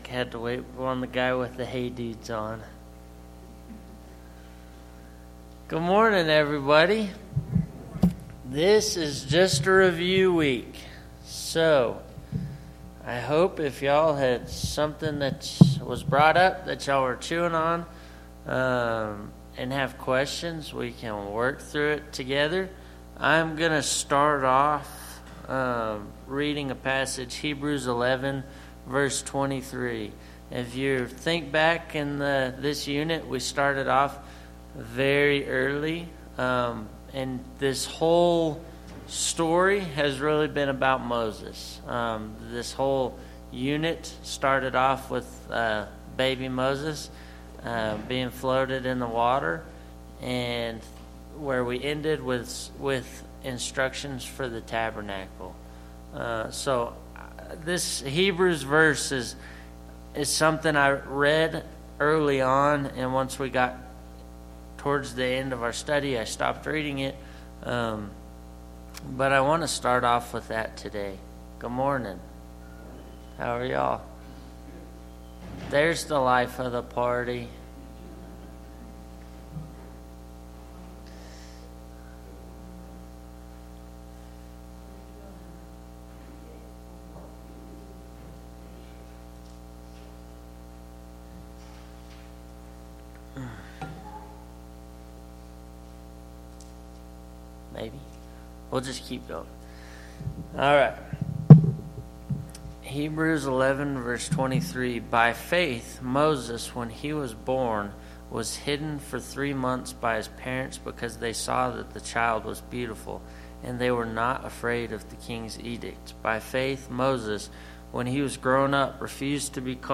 Bible Class 11/03/2024 - Bayfield church of Christ